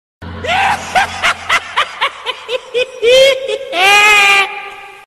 chucky laugh.mp3
chucky-laugh.mp3